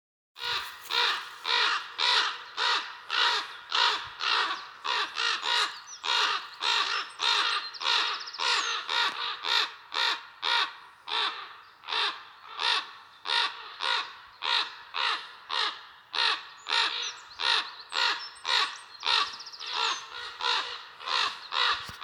Птицы -> Вороны ->
ворон, Corvus corax